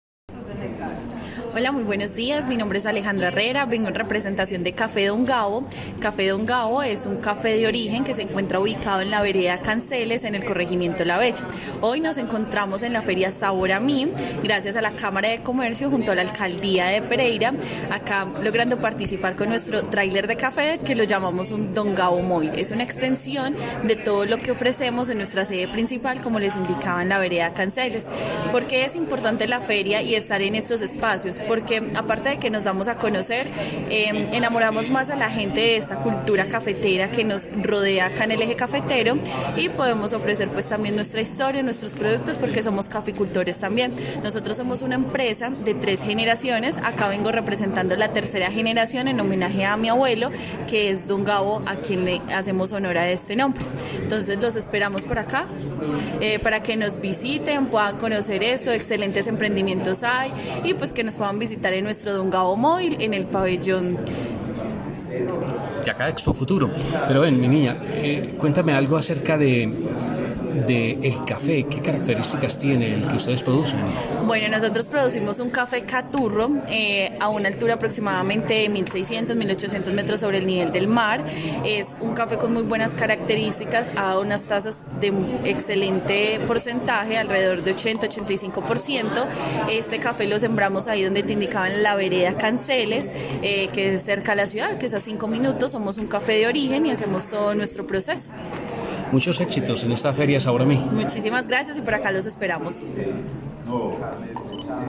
PARTICIPANTE_FERIA.mp3